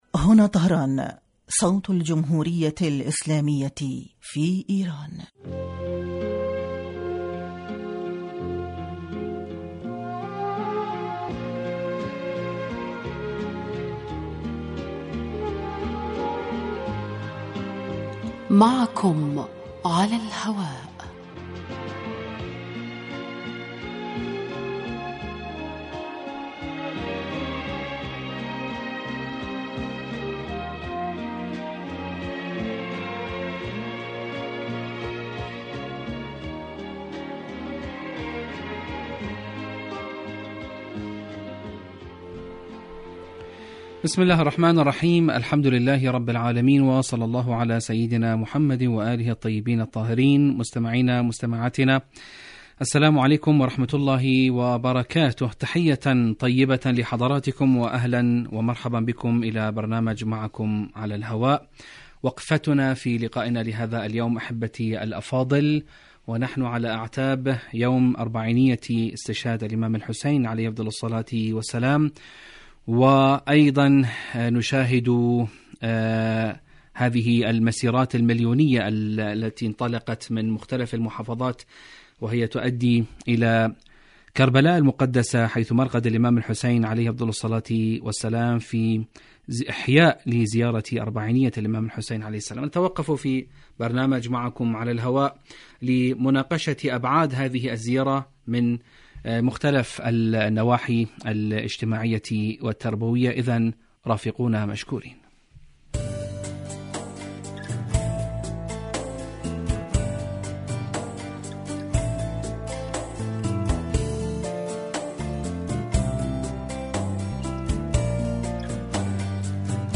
من البرامج المعنیة بتحلیل القضایا الاجتماعیة في دنیا الإسلام و العرب و من أنجحها الذي یلحظ الکثیر من سیاسات القسم الاجتماعي بصورة مباشرة علی الهواء وعبر الاستفادة من رؤی الخبراء بشان مواضیع تخص هاجس المستمعین